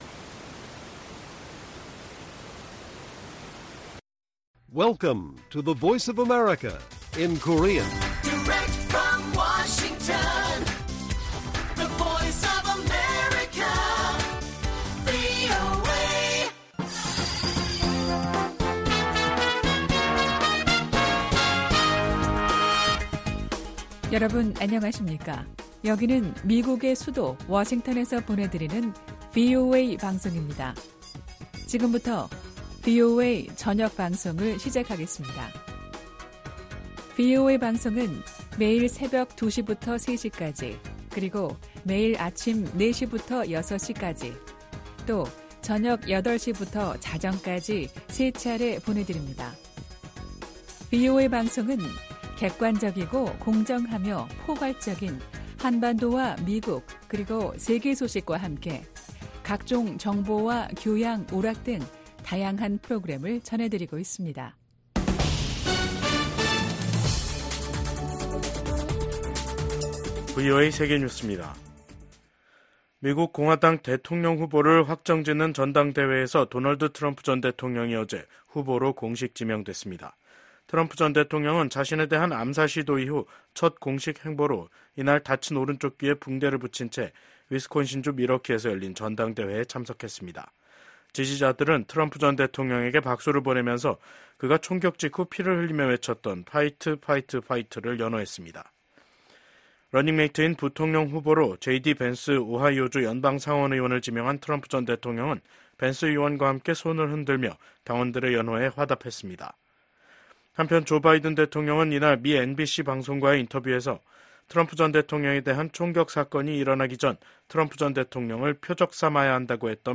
VOA 한국어 간판 뉴스 프로그램 '뉴스 투데이', 2024년 7월 16일 1부 방송입니다. 도널드 트럼프 전 미국 대통령이 공화당 대선 후보로 공식 지명됐습니다.